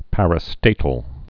(părə-stātl)